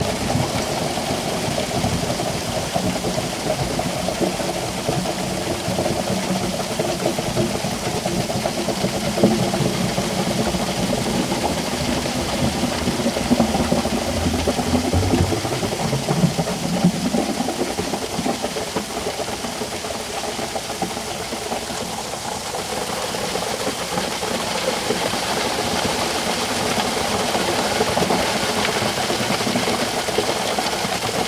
Звуки цемента
Звук цементной смеси вытекающей из бетонного миксера